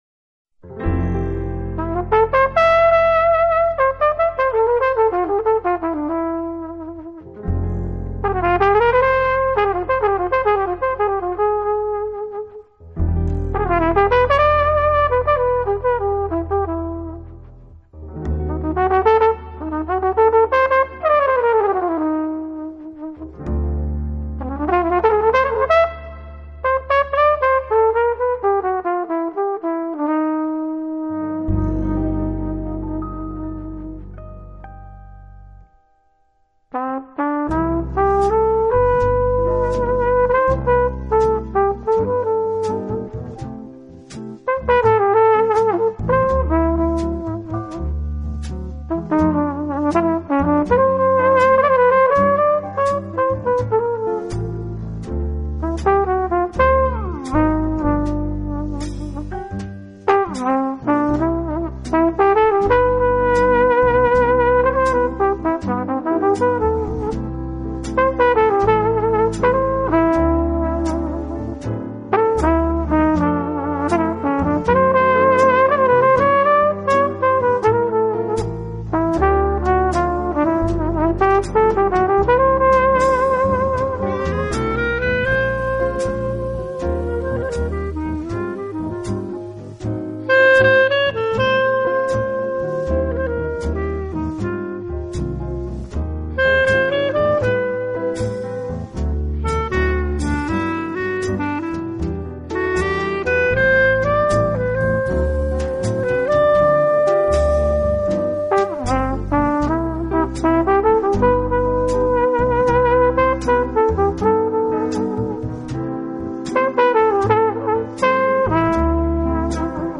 Bop and Swing all on one album!